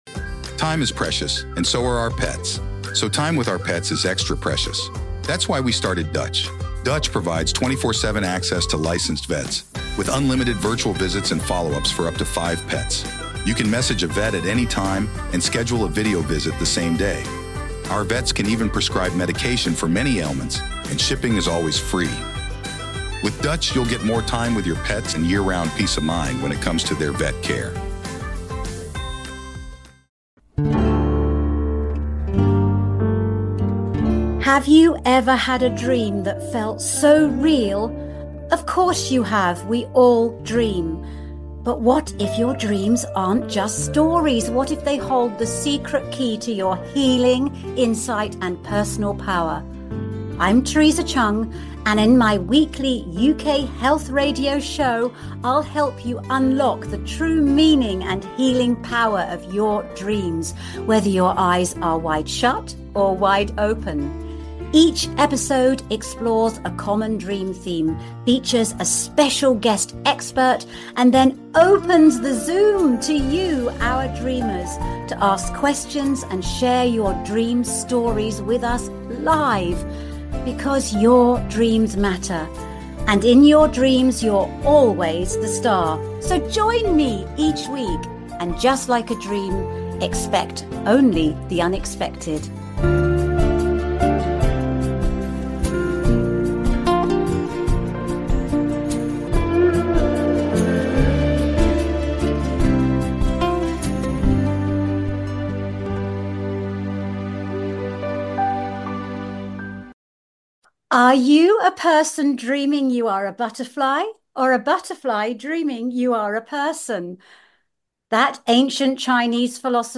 The Healing Power of Dreams explores the transformative power of nocturnal dreams and day dreams for your mental, emotional, physical, and spiritual wellbeing. Each live episode dives deep with leading scientists, psychologists, authors, and consciousness researchers, plus the occasional celebrity guest sharing their own dream stories.